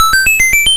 1up.wav